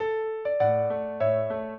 piano
minuet14-12.wav